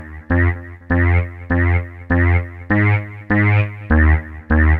Dance music bass loop - 100bpm 54